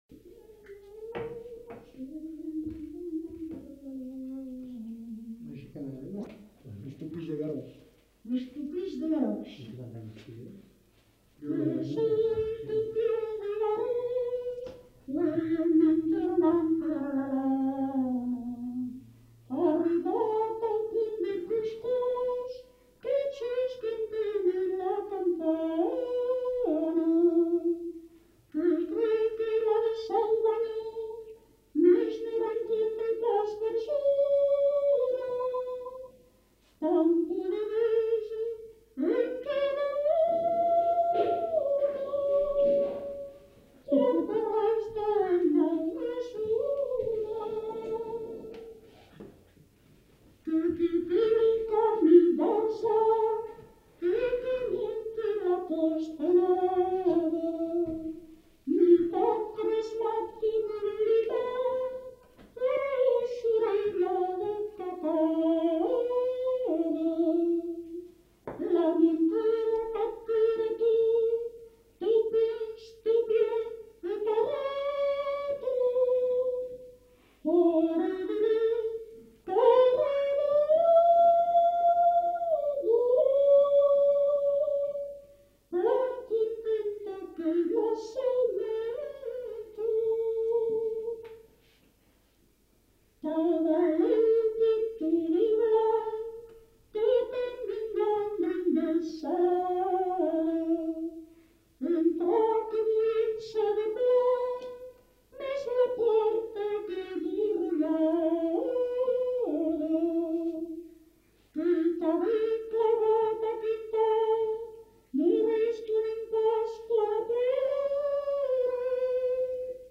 Aire culturelle : Béarn
Genre : chant
Effectif : 1
Type de voix : voix de femme
Production du son : chanté
Commentaires sur la composition de la chanson en fin de séquence.